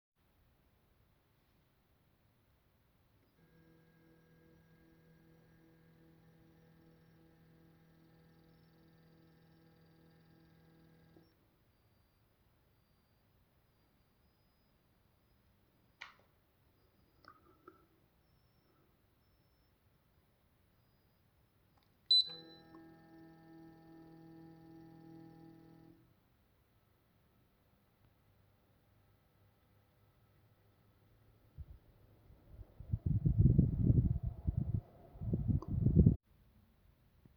3.4s - 11.5s : bruit à environ 160 Hz assez faible, puis silence (pourquoi le bruit cesse-t-il?)
à 16s : un claquement, qu'est-ce?
à 22.2s : bip (suite à quelle action?) suivi du 160 Hz nettement plus fort (micro plus près?) jusqu'à 26s
33s - 36s : vent (ventilo qui tourne?) qui cesse brutalement (pourquoi?)